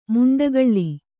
♪ muṇḍagaḷḷi